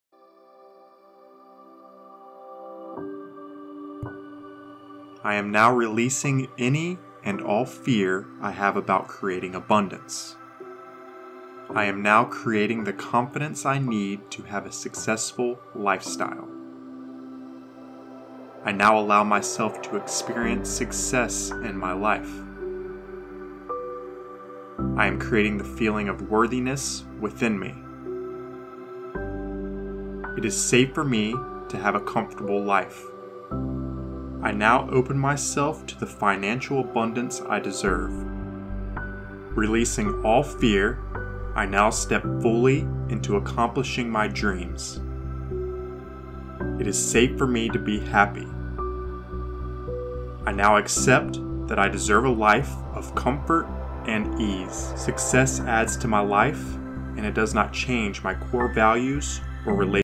This is the meditative version of this track.
preview-Affirmations-to-Release-Fear-and-Resistance-About-Success-and-Money-Meditation-Version.mp3